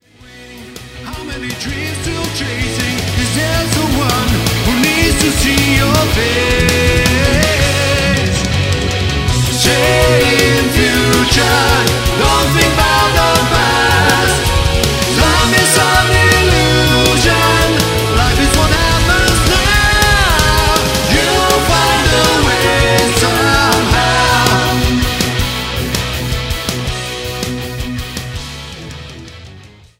ジャンル HardRock
Progressive
シンフォニック系